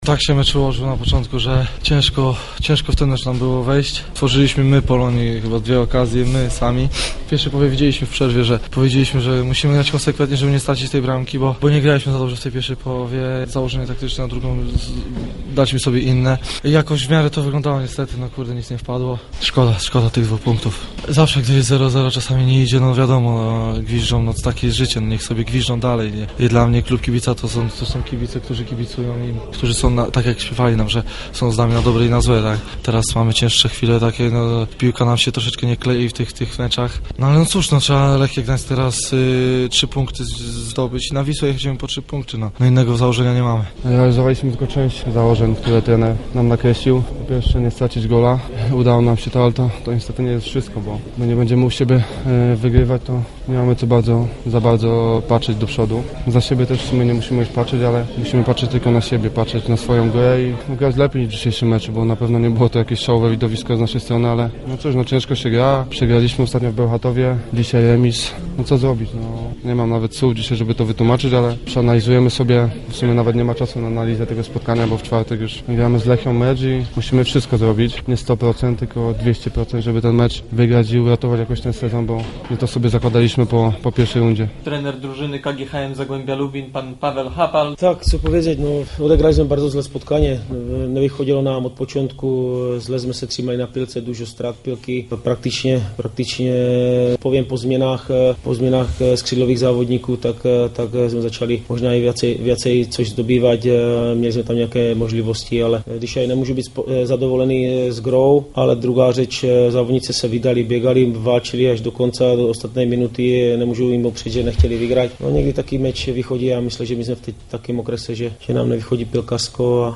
Pomeczowe komentarze